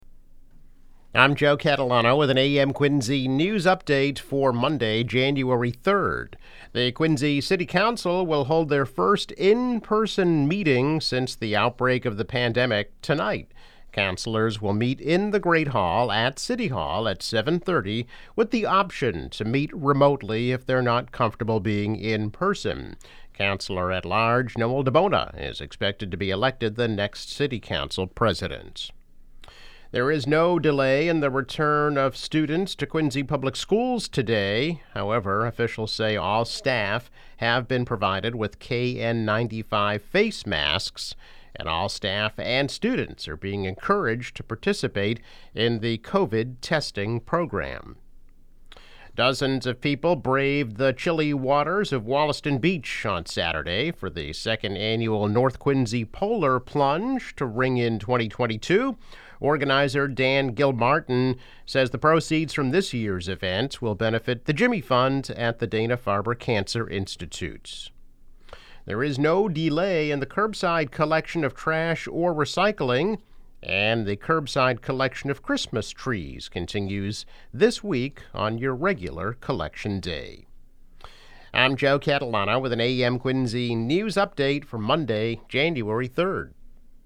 News Update - January 3, 2022